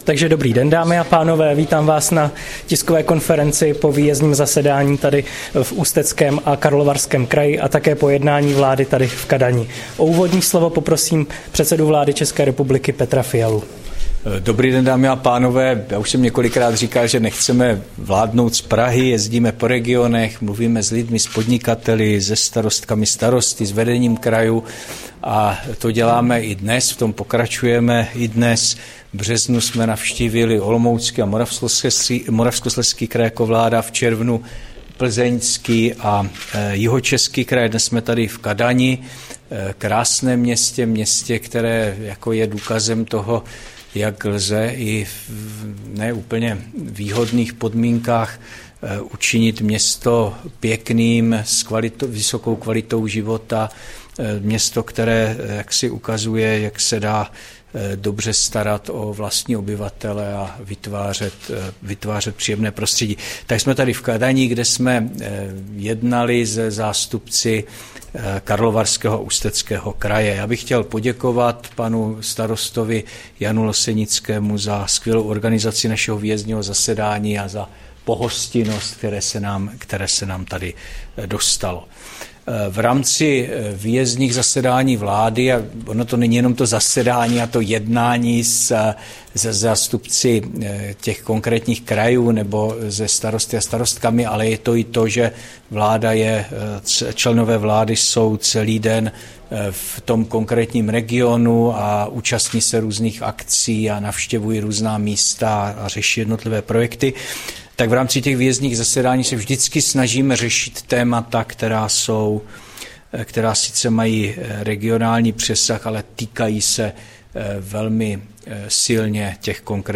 Tisková konference po výjezdním zasedání vlády v Kadani, 20. září 2023